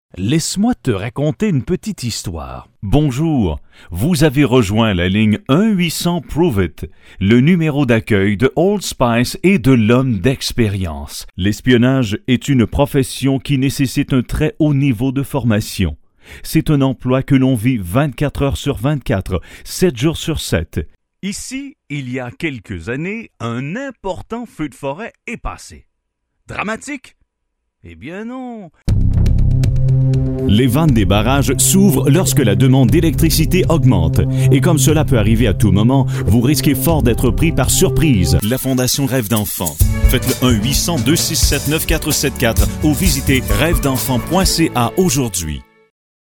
Authentic french canadian male voice
Sprechprobe: Sonstiges (Muttersprache):